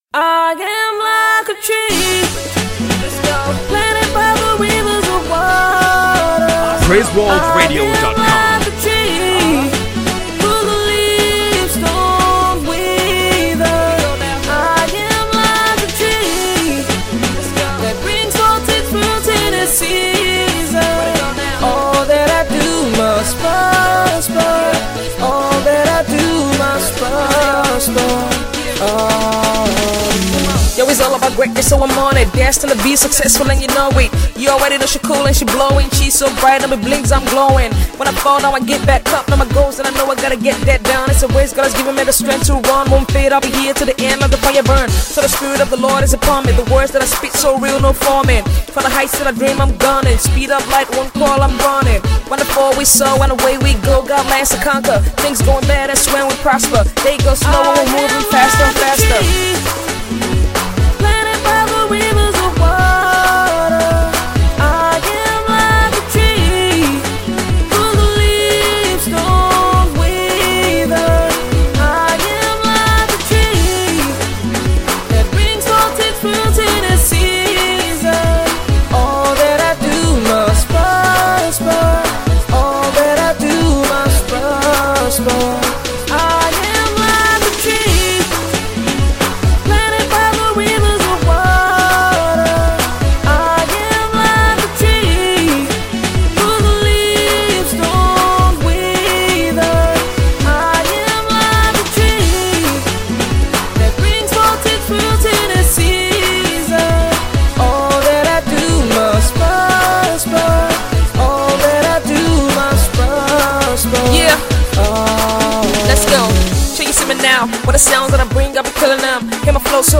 Urban gospel